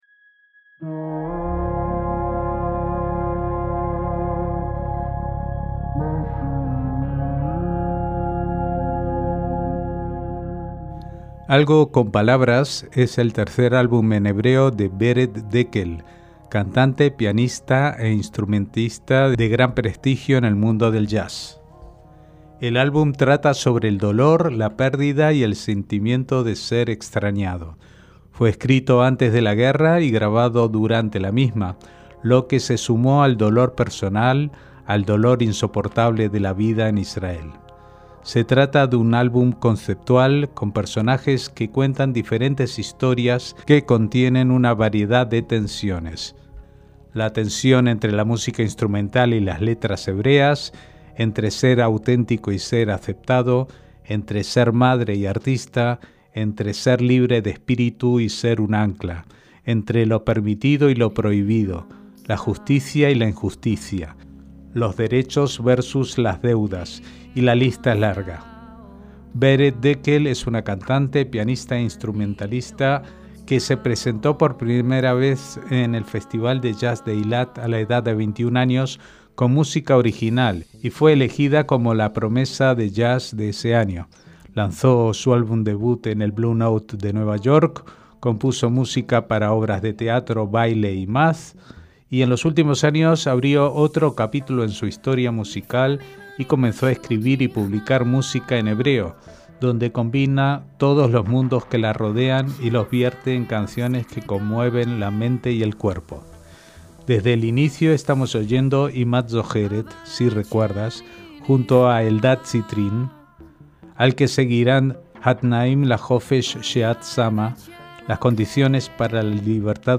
MÚSICA ISRAELÍ -&nbsp